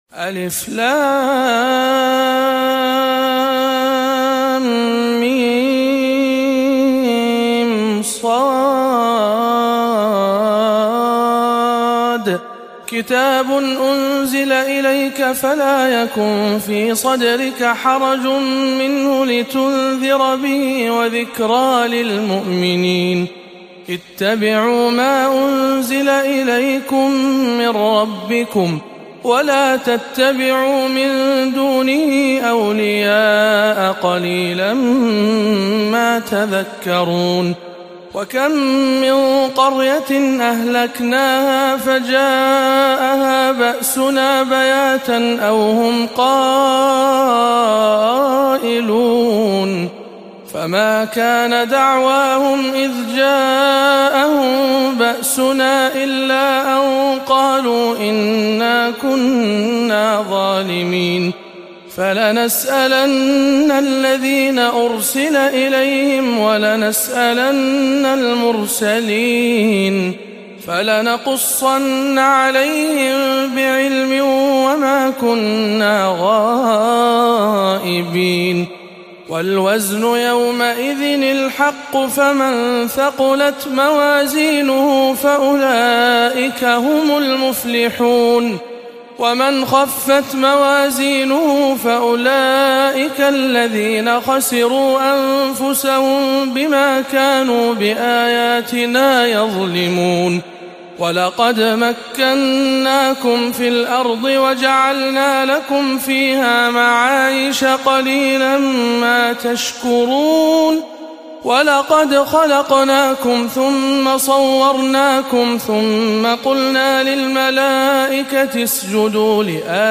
سورة الأعراف بجامع أم الخير بجدة